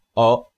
In general, Spanish vowels are shorter than English vowels and the sounds are made at more exaggerated tongue positions than English.
4. The Spanish ‘o’
The Spanish ‘o’ is similar to the ‘o’ in ‘more’.
Spanish-O.mp3